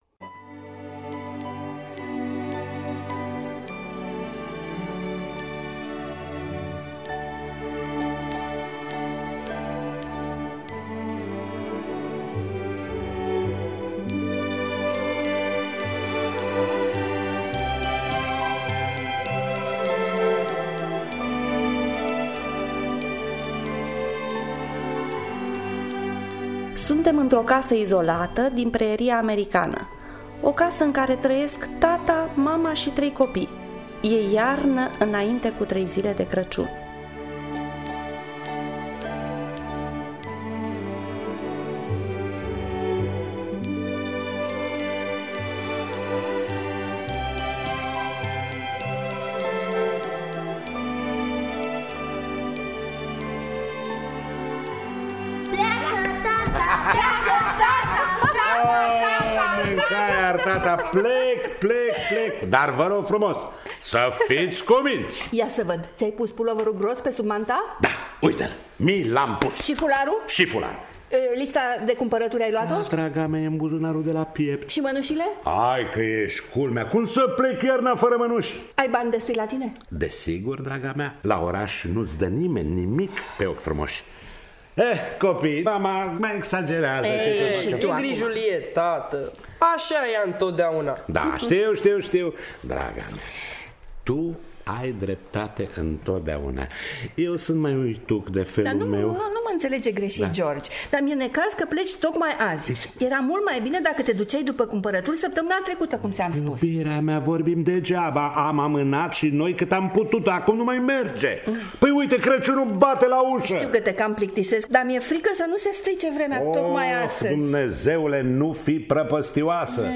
Crăciun sub zăpadă de Olive Thorne Miller – Teatru Radiofonic Online